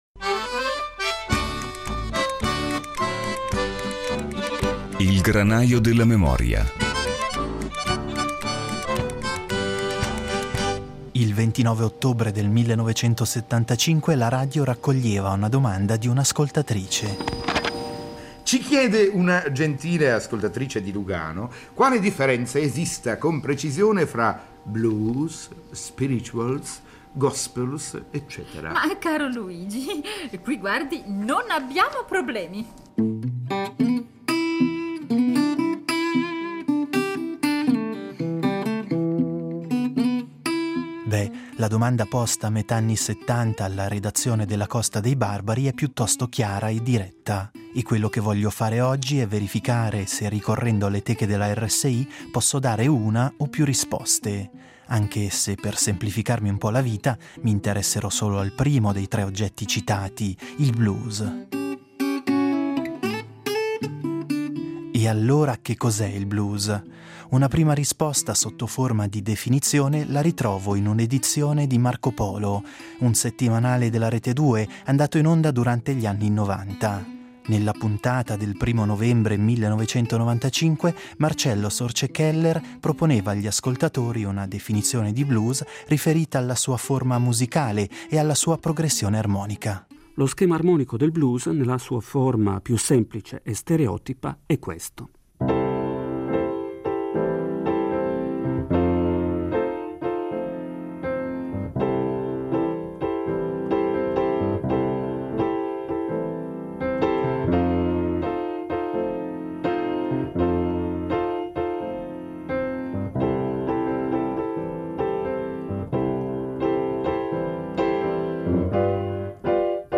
La radio il blues lo ha avvicinato in modi diversi: tramettendo brani e canzoni; analizzandone le caratteristiche compositive; raccontandone le storie e gli interpreti. Curioso, "Il Granaio della memoria" riscopre questa settimana quei documenti sonori che, tra teche della RSI, compongono una memoria interamente colorata di blues.